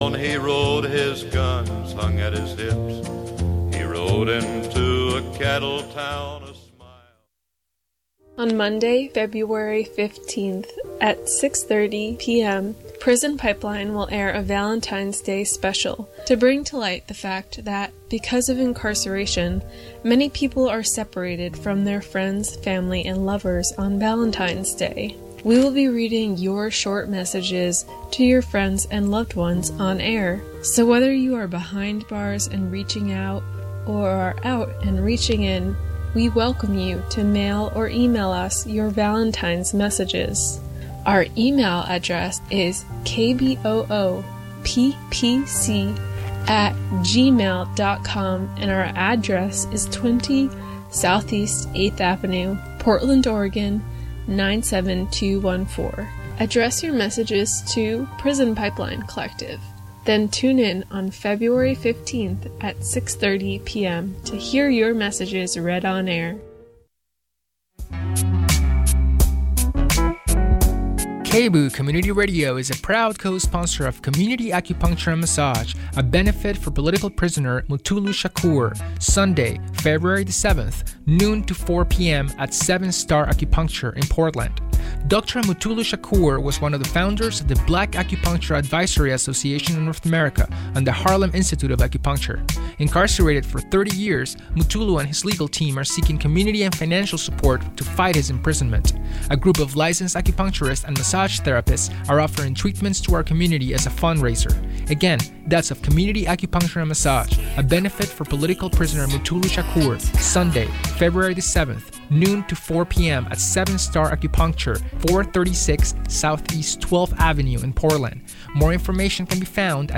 Report Back from COP21: Climate Justice Panel,